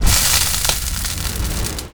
bzzt.wav